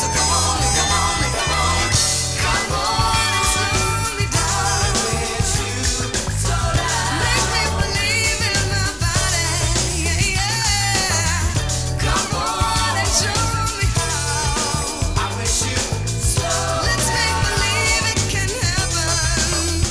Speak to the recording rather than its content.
8 bit mono From the Demo Tape